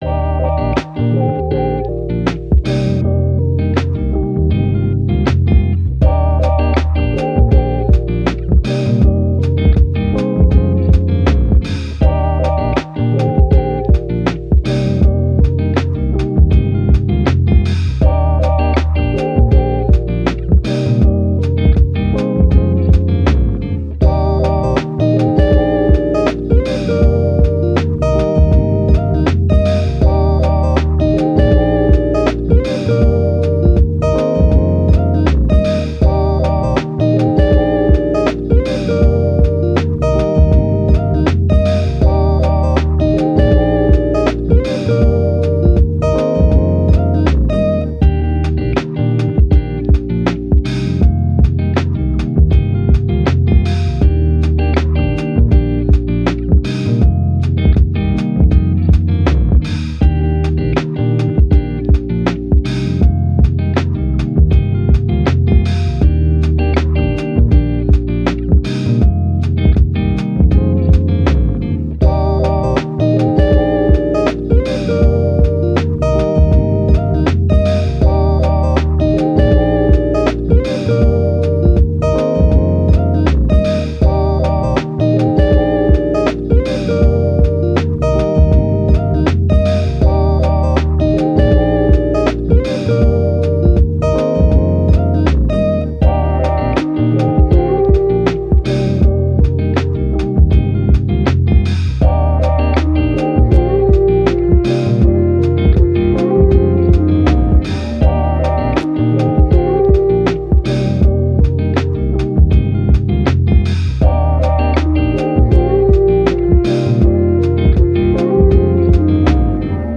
Lofi, Hip Hop, Chill, Playful